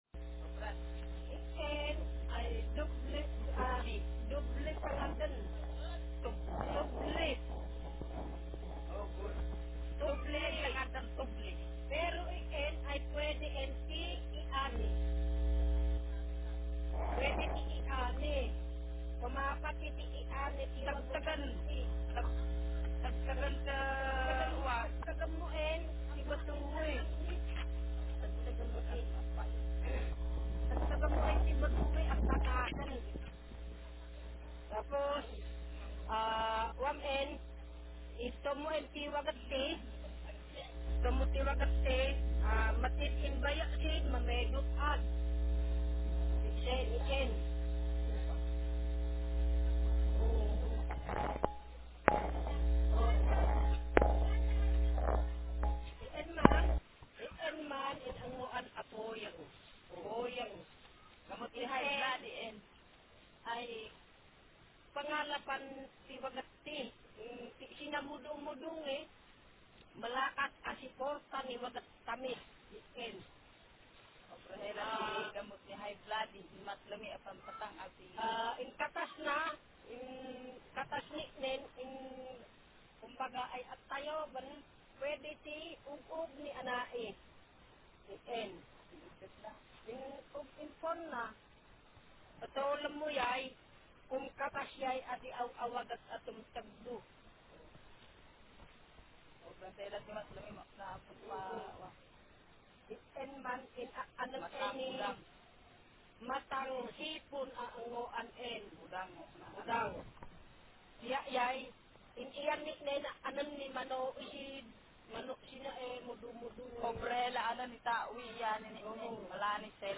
Speaker sex f Text genre procedural